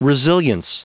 Prononciation du mot resilience en anglais (fichier audio)
Prononciation du mot : resilience